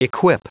Prononciation du mot equip en anglais (fichier audio)
Prononciation du mot : equip